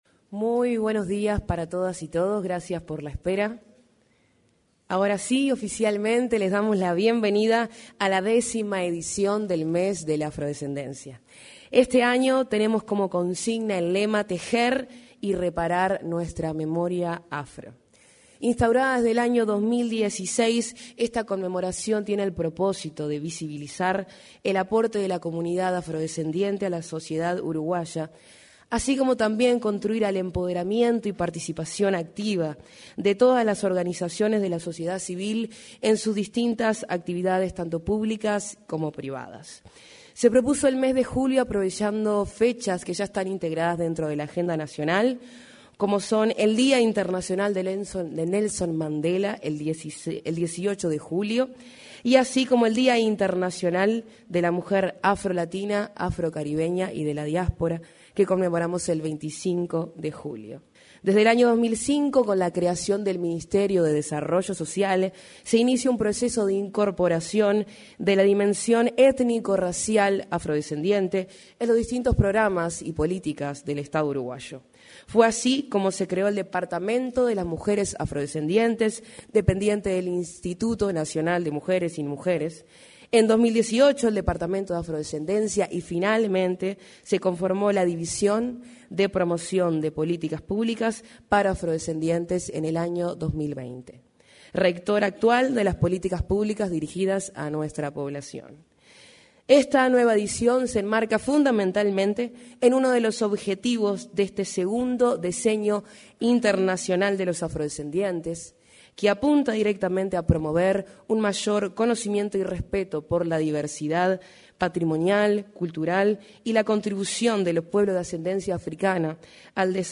En la presentación del Mes de la Afrodescendencia, se expresaron la directora de la División de Promoción de Políticas Públicas para Afrodescendientes